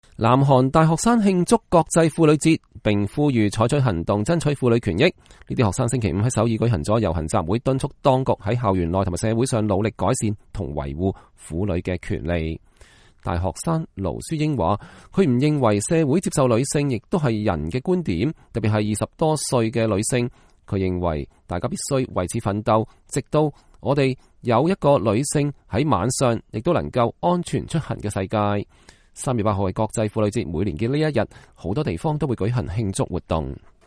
這些學生星期五在首爾舉行了遊行集會，敦促當局在校園內和社會上努力改善和維護婦女權利。